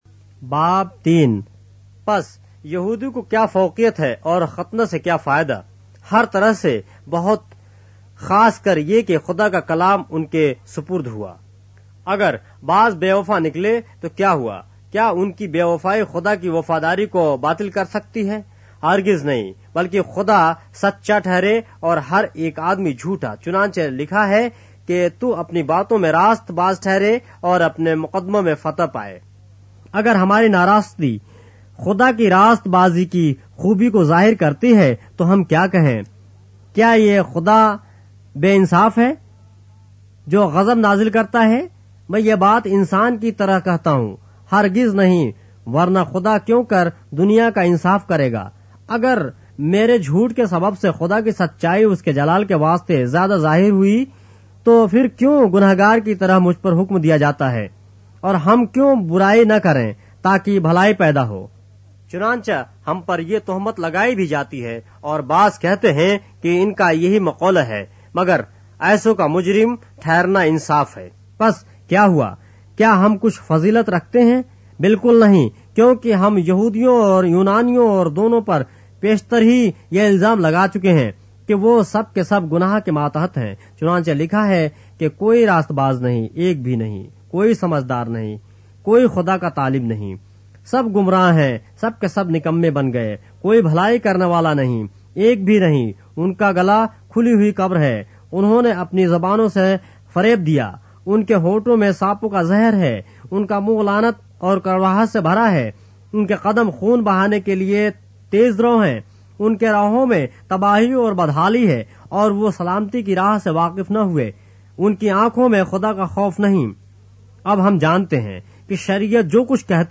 اردو بائبل کے باب - آڈیو روایت کے ساتھ - Romans, chapter 3 of the Holy Bible in Urdu